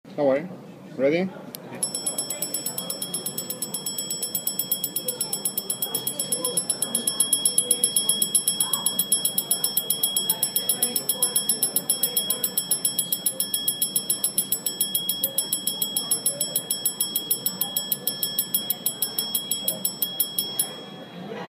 The alarm will not prick the wearer but is sounded with hammer strikes.
Based on our hands-on experience with the Cyrus Klepcys Réveil, the alarm sounded for around 19 seconds. Perhaps the weaker strikes (as the power winds down) towards the end are not counted.
We actually recorded the alarm of the Cyrus Klepcys Réveil at the Cyrus booth during the 2015 BaselWorld watch fair. Despite the background noise, the Cyrus alarm was very audible.
CyrusAlarm.mp3